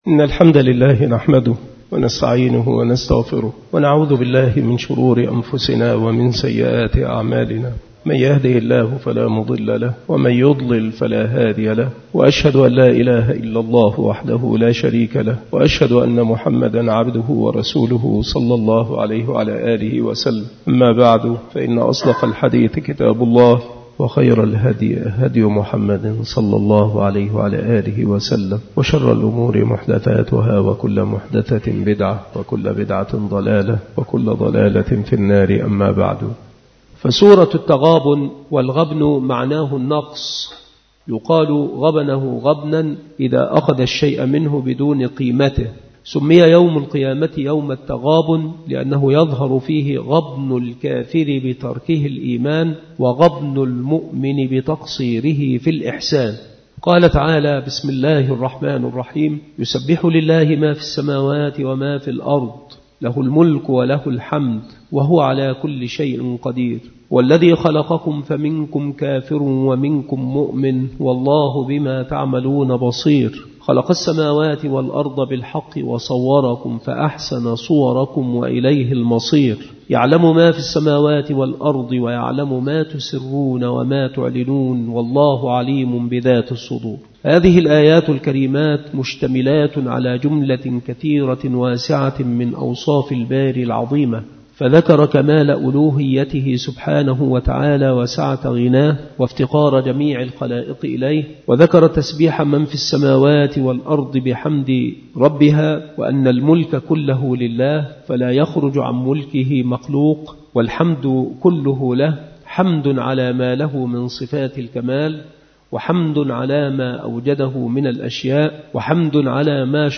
التفسير
مكان إلقاء هذه المحاضرة بالمسجد الشرقي بسبك الأحد - أشمون - محافظة المنوفية - مصر